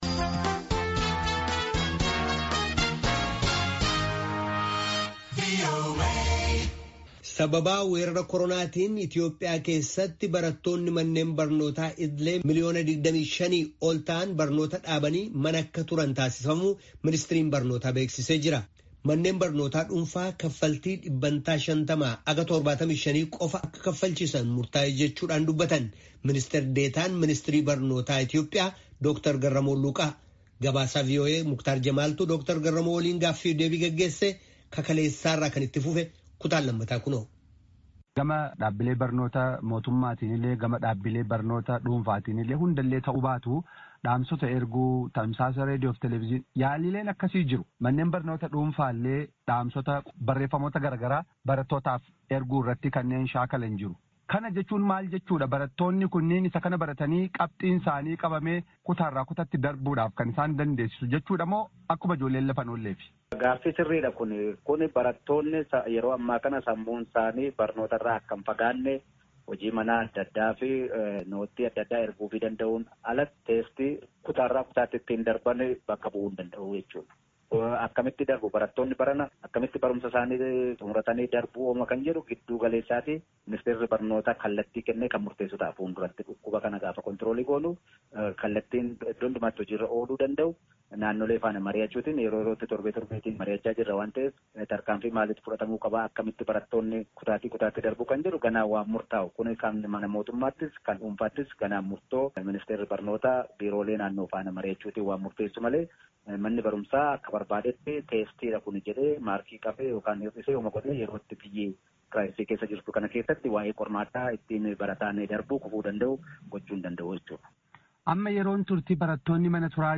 Gaaffii fi deebii Dr. Garamoo waliin geggeessame Kutaa 2ffaa